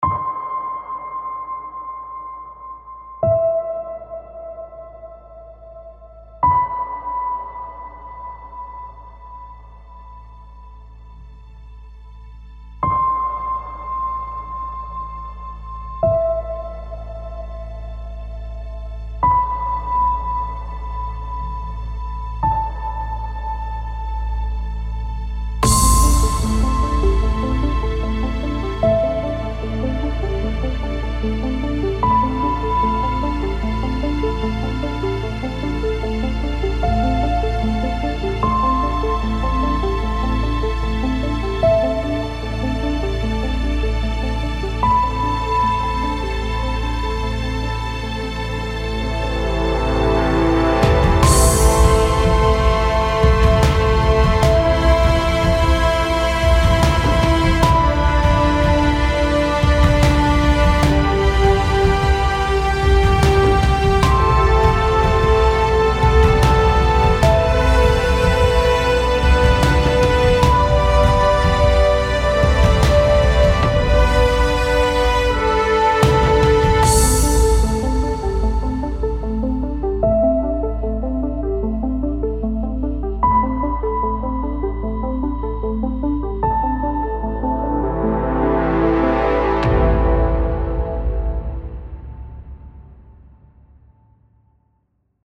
适用于所有电影风格-从激进到振奋